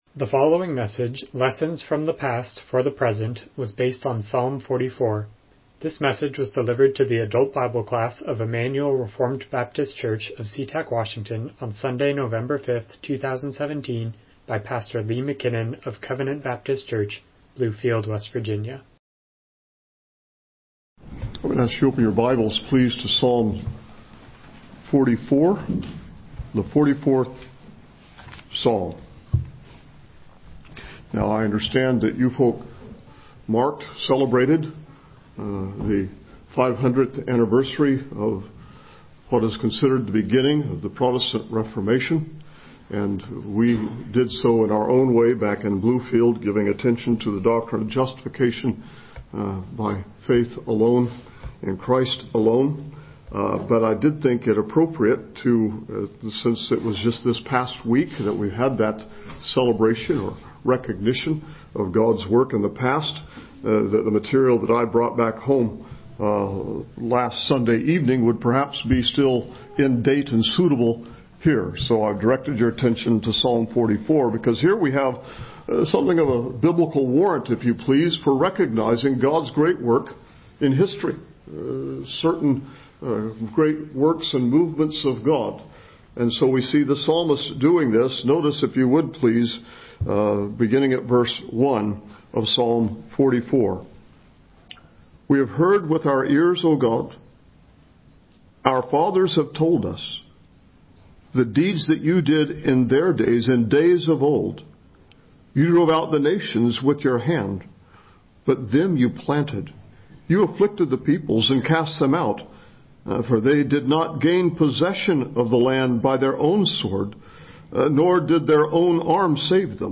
Miscellaneous Passage: Psalm 44:1-26 Service Type: Sunday School « Absalom’s Defeat